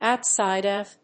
outsíde of… 《米口語》